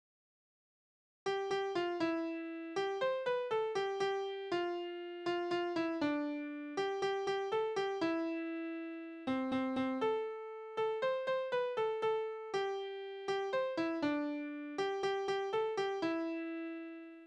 Tonart: C-Dur
Taktart: 4/4
Tonumfang: Oktave
Besetzung: vokal